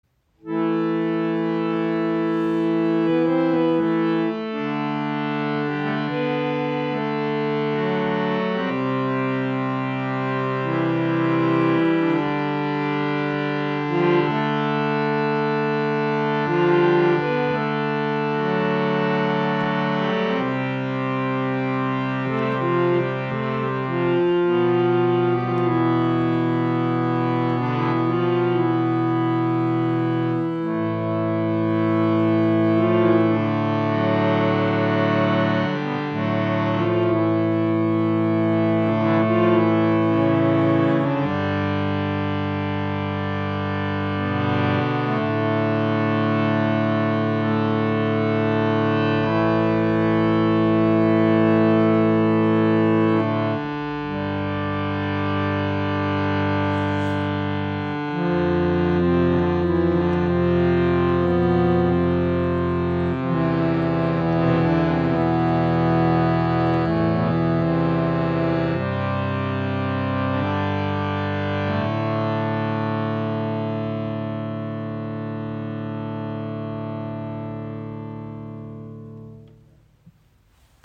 Wenn es um einen unglaublich langen, kompromissloses Sustain, sanftes Spiel und reiche, dynamische Klangvielfalt geht, ist das Bhava Studio unübertroffen.
Anschläge: 5 (Male, Bass, Bass, Tremolo, Male)
Bordune: 4 (C, D, G, A)
Stimmung: Concert Pitch / 440 Hz
Warme Tonalität: Resonanz durch ein massives Teakholzgehäuse